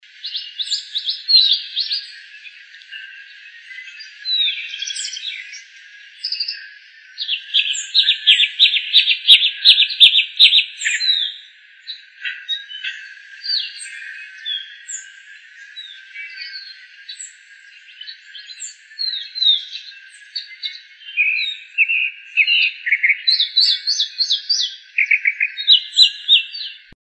Calandria Grande (Mimus saturninus)
Nombre en inglés: Chalk-browed Mockingbird
Localidad o área protegida: Reserva Ecológica Costanera Sur (RECS)
Condición: Silvestre
Certeza: Vocalización Grabada
RECS.Calandria-Grande-3-VN.mp3